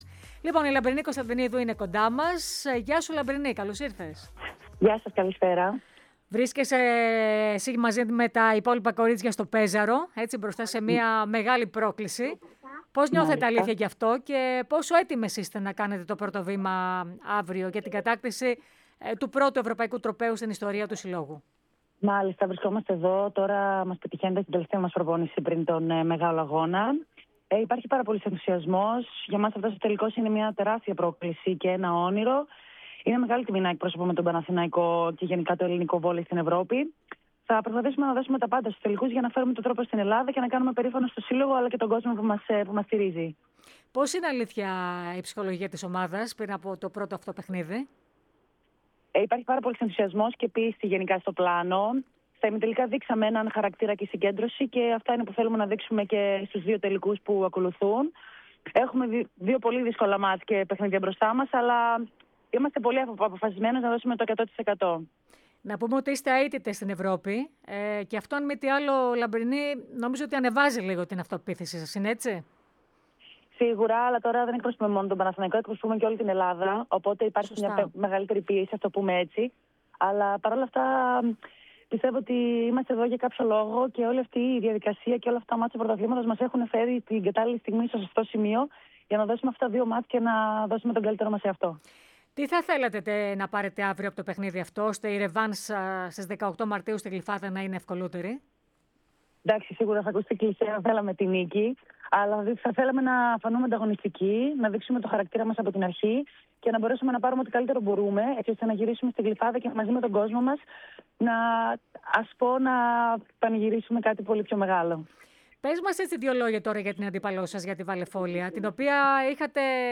μίλησε στην εκπομπή "Extra Spor" της ΕΡΑ ΣΠΟΡ